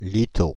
Litteau (French pronunciation: [lito]
Fr-Litteau.ogg.mp3